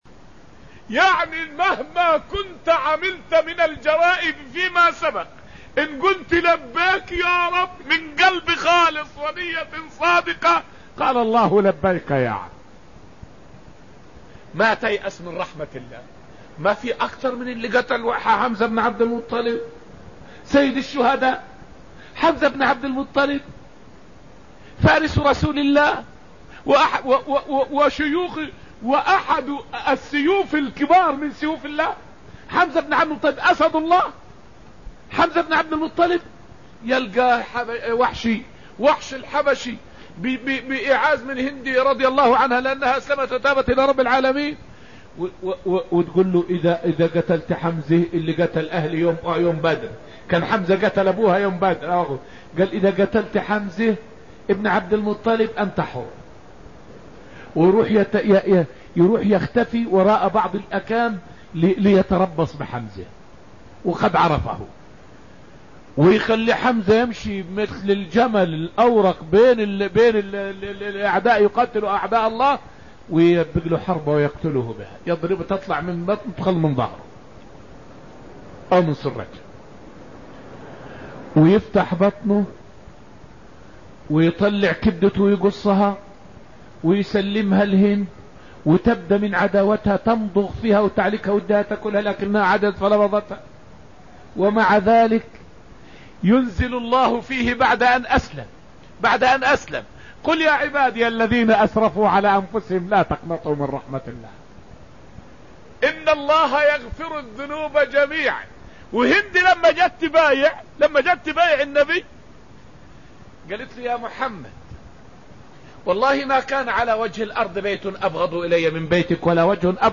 فائدة من الدرس الثاني عشر من دروس تفسير سورة الحشر والتي ألقيت في المسجد النبوي الشريف حول أثر الكسب الحلال في صلاح القلب واستجابة الدعاء.